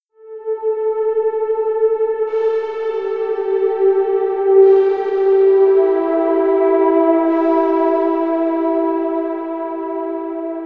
Synth 30.wav